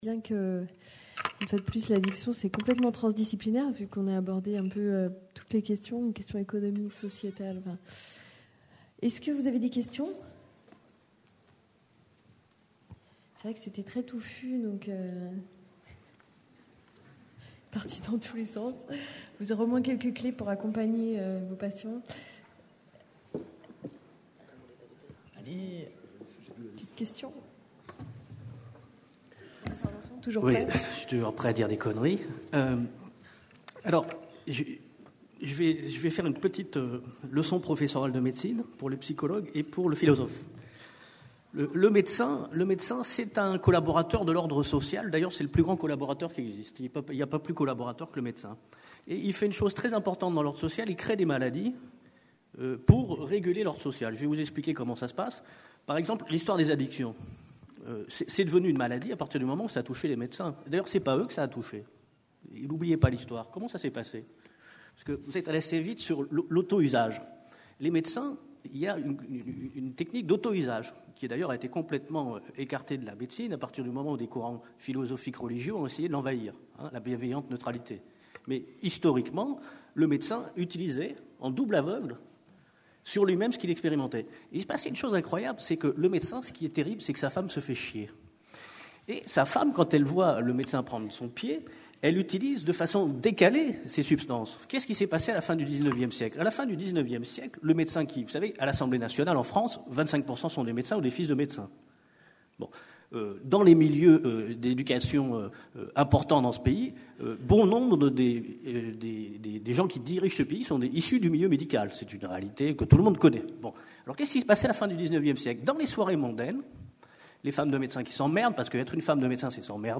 CNIPsy 2010 Marseille - Prévenir et prendre en charge les conduites addictives. Débat.